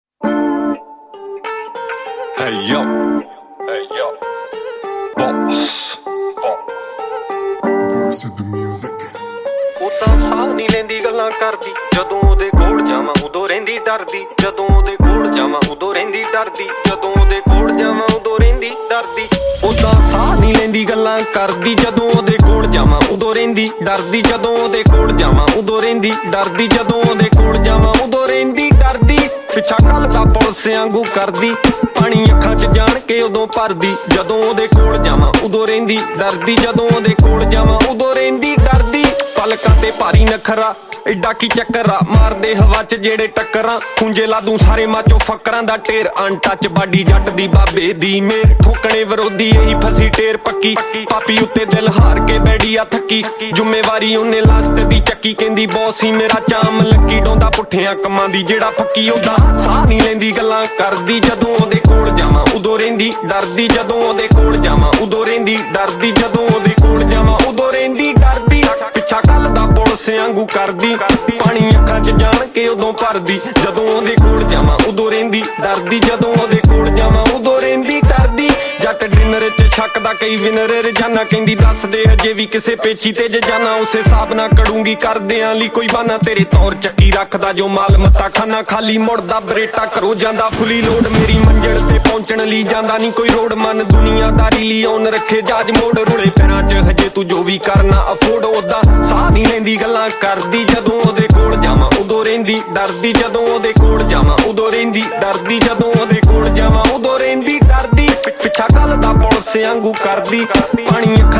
Releted Files Of Latest Punjabi Song